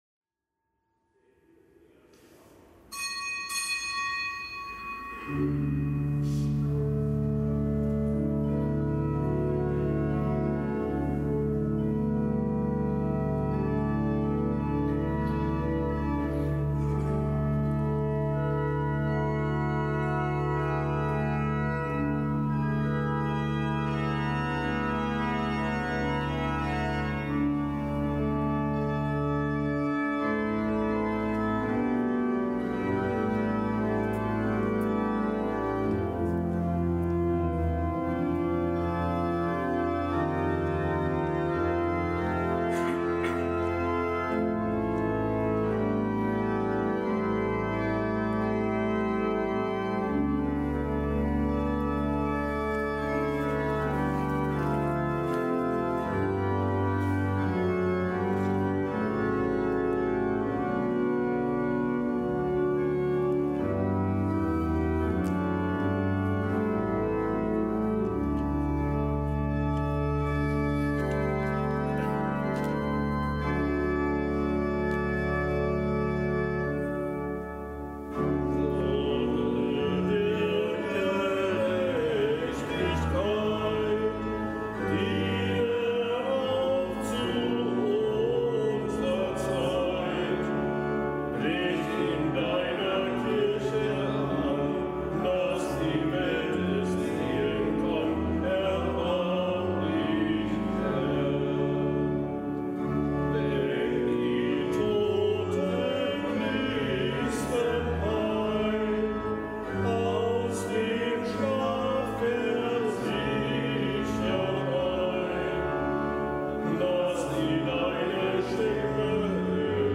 Kapitelsmesse aus dem Kölner Dom am Gedenktag des Heiligen Josaphat, einem Märtyrer und Bischof von Polozk in Belarus, Weißrussland. Zelebrant: Weihbischof Rolf Steinhäuser.